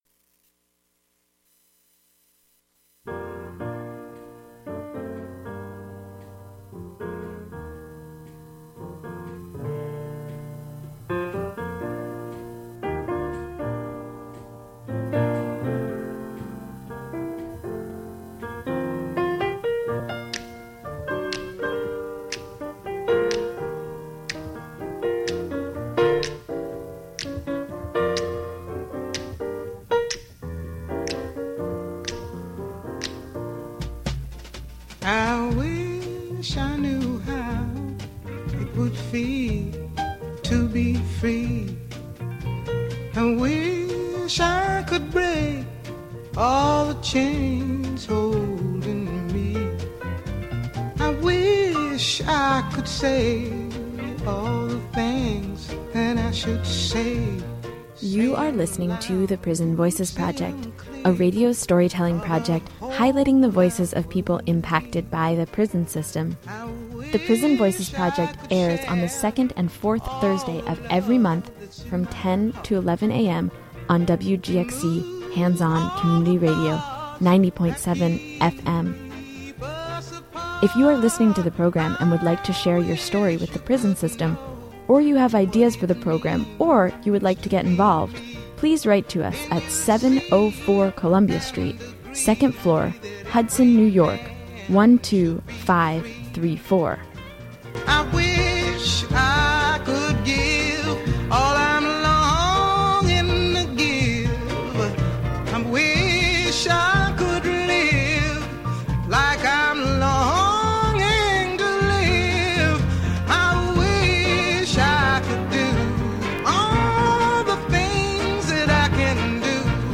This week's show will include an update and re-broadcast of a previous show